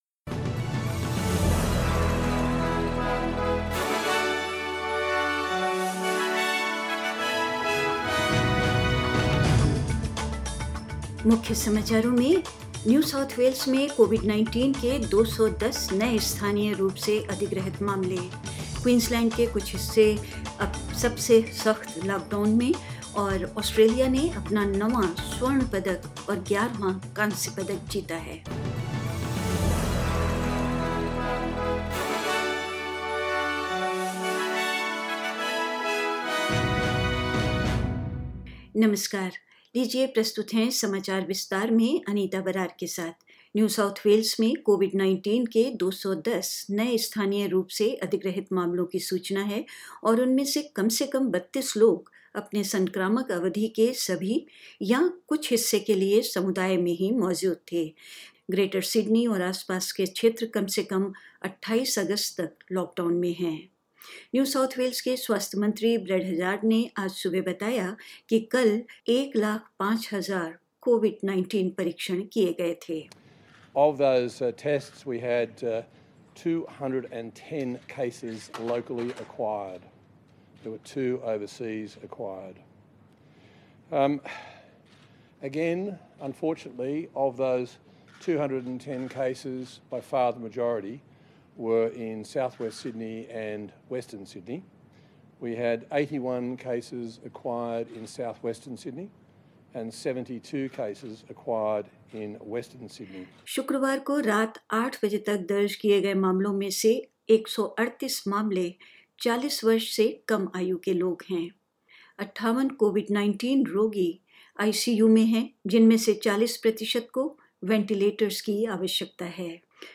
In this latest SBS Hindi News bulletin of Australia and India: NSW has reported 210 new locally acquired cases of COVID-19; Meanwhile, parts of Queensland descend into the state's strictest lockdown so far, as six new cases are recorded; Australia wins its ninth gold medal and 11th bronze; In India, Assam and Mizoram border dispute and more